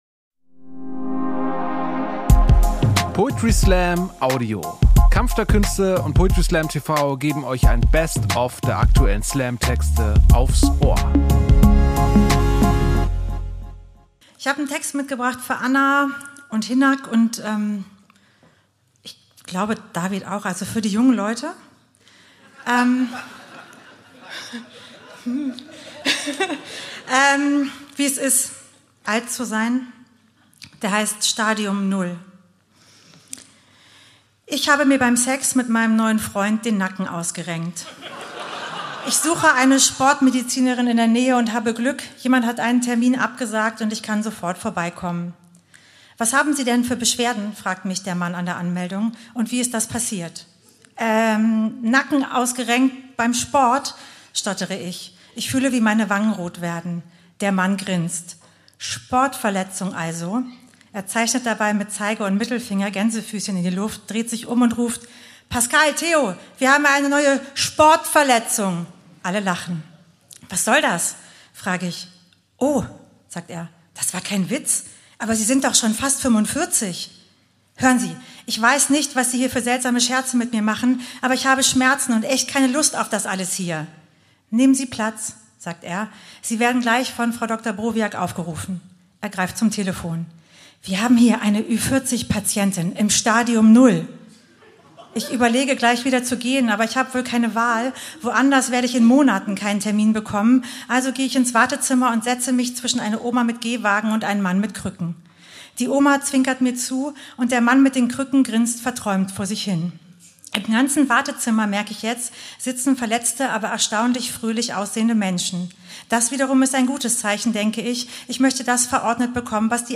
Kunst , Comedy , Gesellschaft & Kultur
Stage: Schauspielhaus, Hamburg